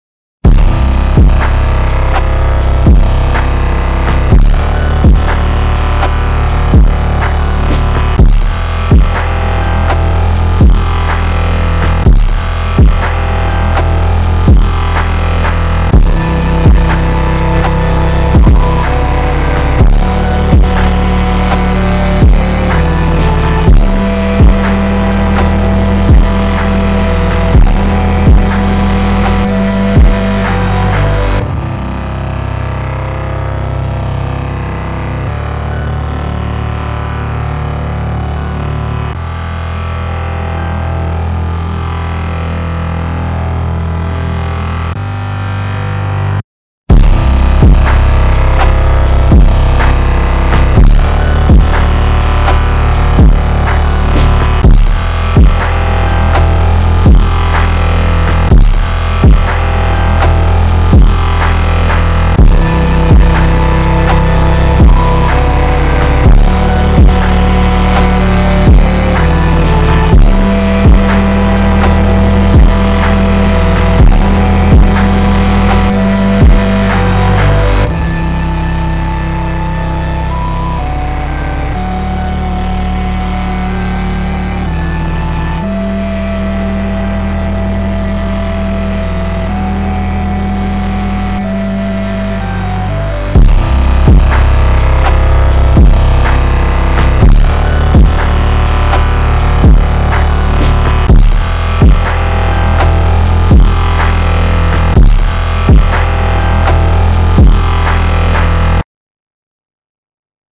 official instrumental
Pop Instrumentals